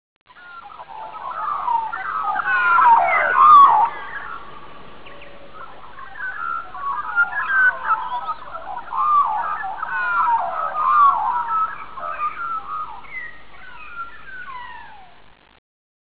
La magpie
chant très agréable.
magpie.wav